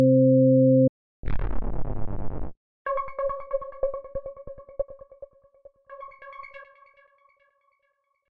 鼓 贝斯 吉他 " 贝斯合成器2
描述：由FL工作室创作的低音合成音